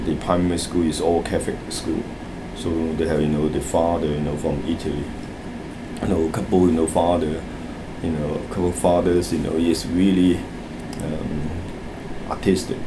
S2 = Hong Kong male Context: S2 is talking about learning art when he was at school.
Italy is said as [ɪti] with two syllables instead of three, while couple is said quite fast, and there is no clear [l] in the word. In fact, the second token is just a single syllable: [kaʊ] .